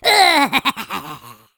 Fantasy Creatures Demo
gnome_laught_1.wav